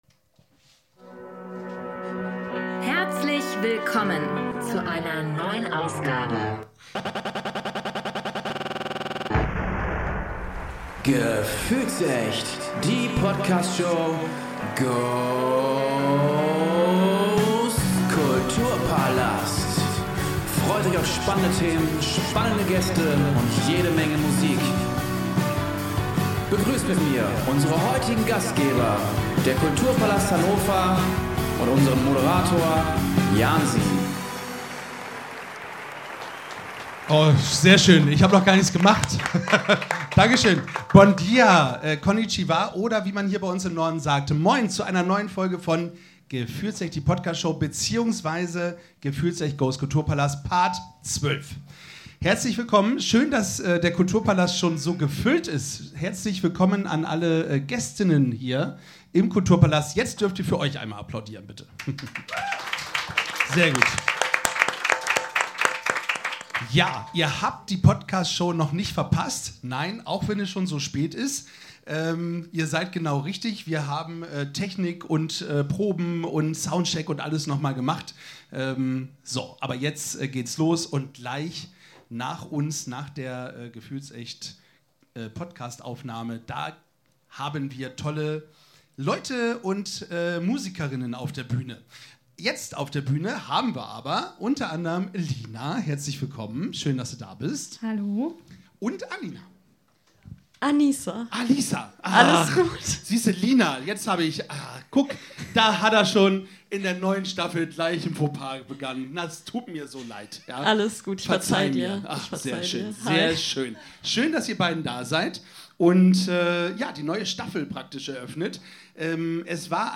Beschreibung vor 1 Jahr Die Sommeroause ist vorbei und wir sind wieder LIVE im Kulturpalast Hannover!
Gemeinsam sprechen sie über ihre Erlebnisse in Irland, teilen ihre Erfahrungen aus der Musikbranche und verraten, was in Zukunft noch so alles geplant ist. Natürlich wird auch gesungen!